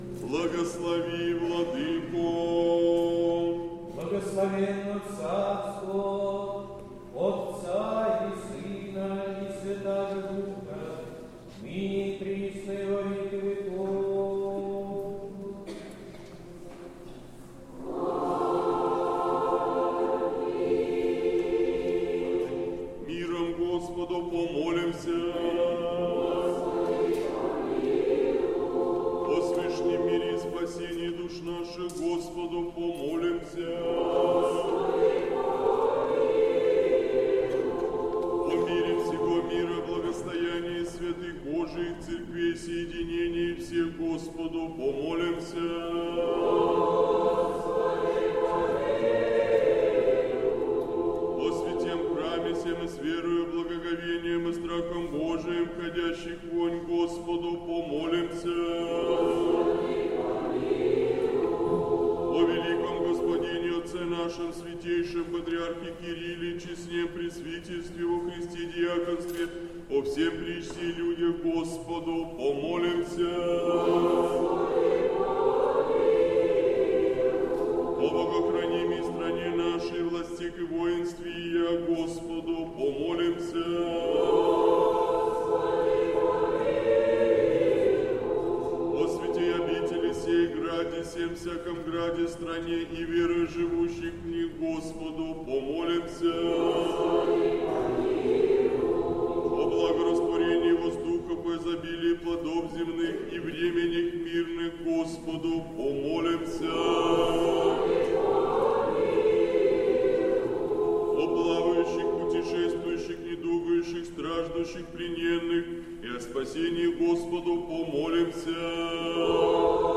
Божественная литургия в Сретенском монастыре в день памяти прп. Сергия Радонежского
Сретенский монастырь. Божественная литургия. Хор Сретенской Духовной семинарии, народный хор Сретенского монастыря.